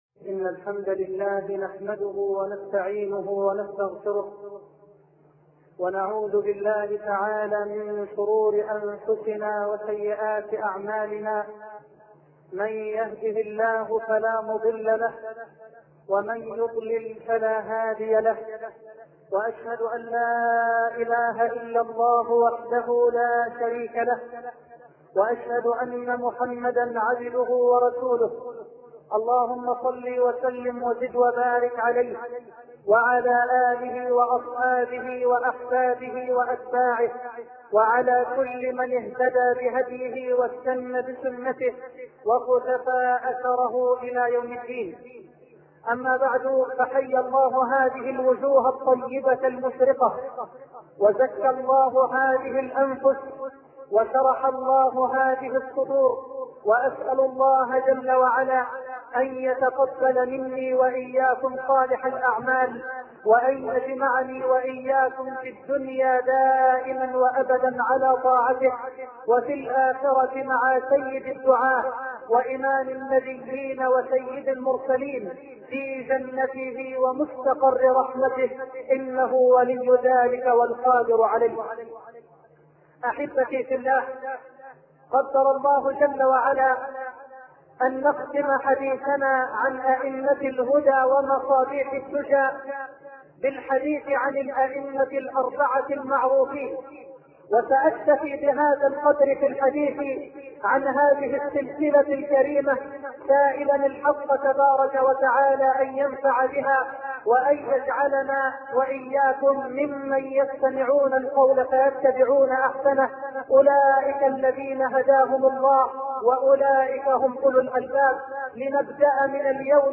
شبكة المعرفة الإسلامية | الدروس | من السبع الموبقات - الشرك بالله |محمد حسان